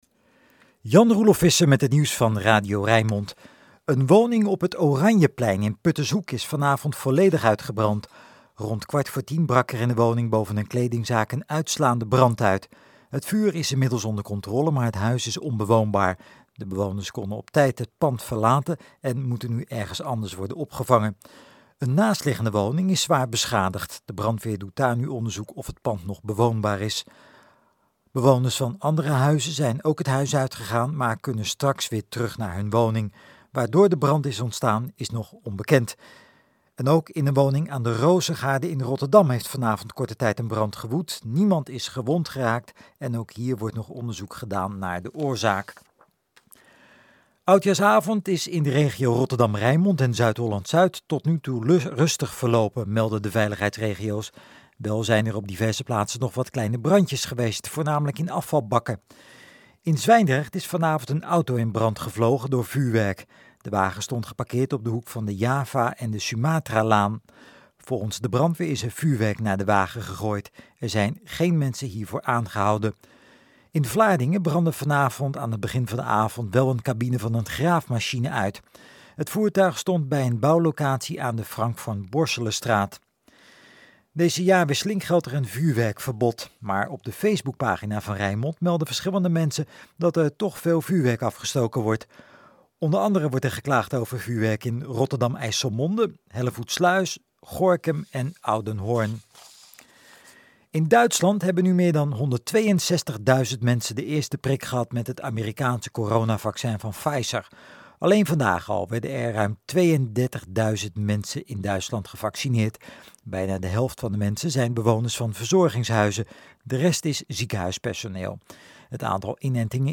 Radio Rijnmond nieuws_audio-mp3.mp3